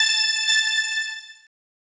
SYNTHPAD003_DISCO_125_A_SC3.wav